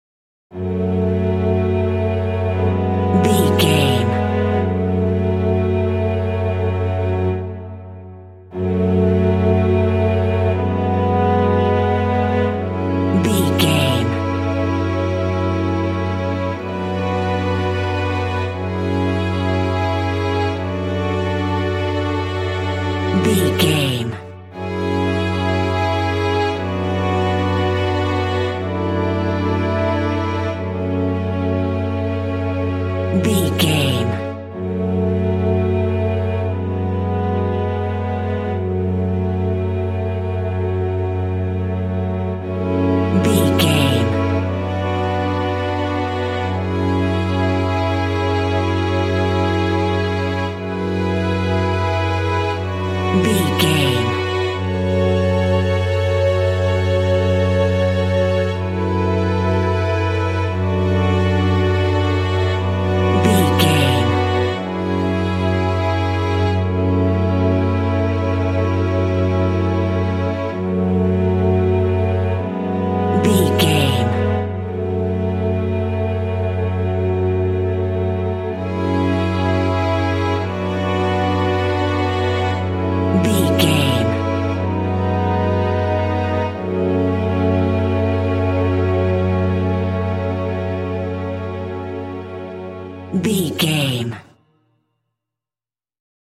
Aeolian/Minor
F#
mystical
dreamy
dramatic
strings